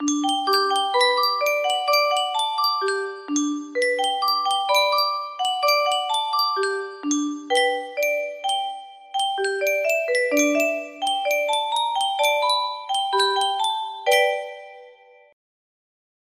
Yunsheng Music Box - Scott Joplin Maple Leaf Rag 1334 music box melody
Full range 60